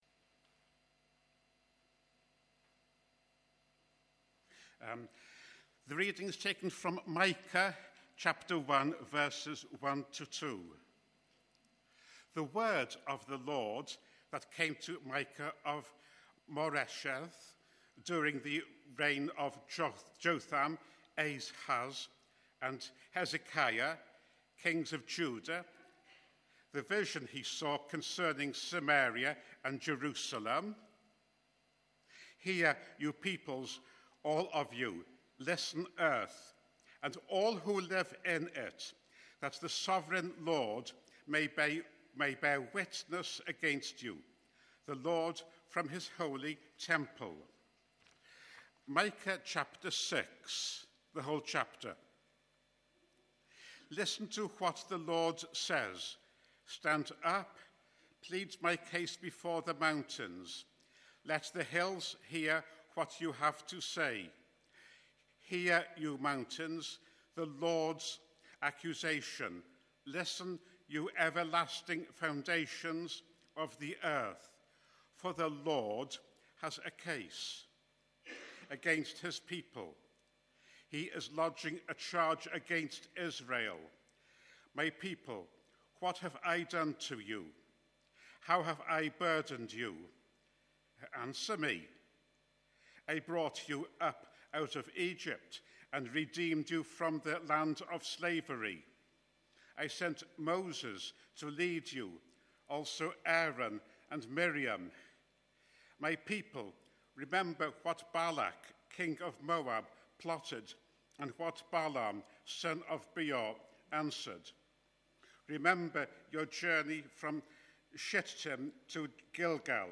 Micah 6 - The fifth sermon in the series 'Minor Prophets'